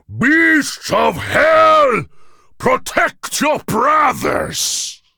G_beast.ogg